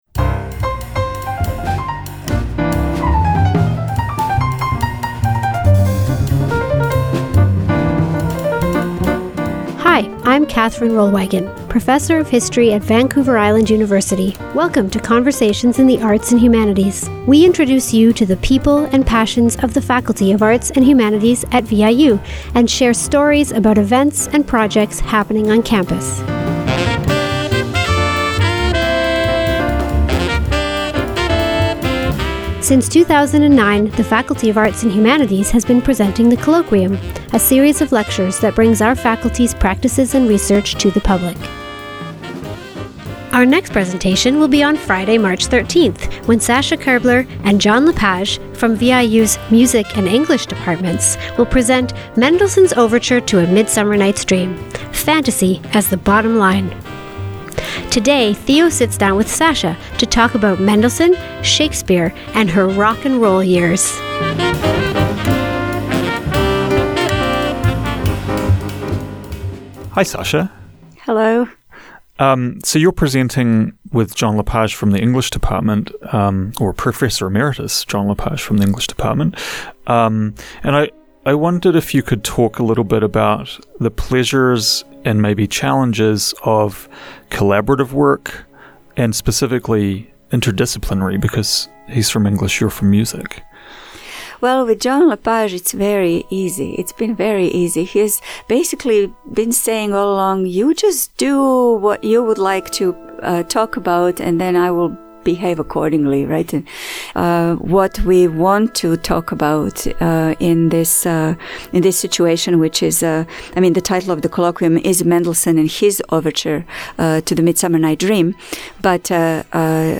Featured are excerpts from the London Symphony Orchestra's performance of this work.